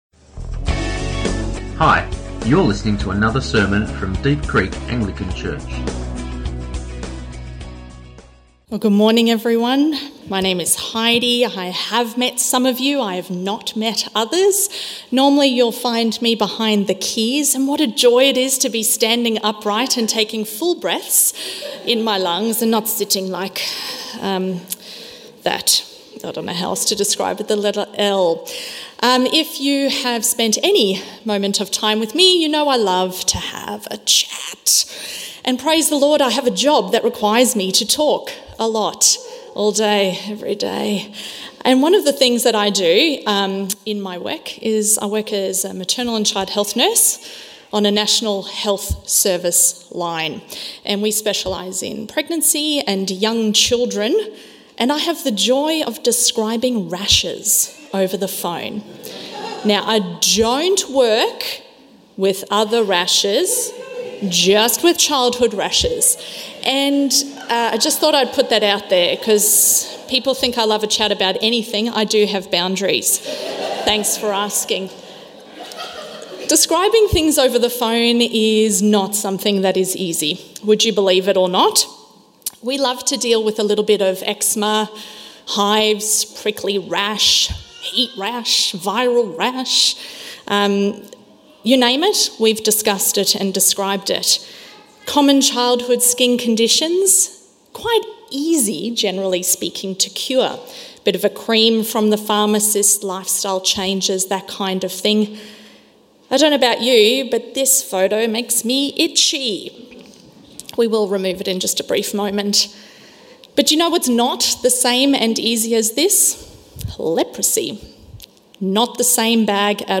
Sermons | Deep Creek Anglican Church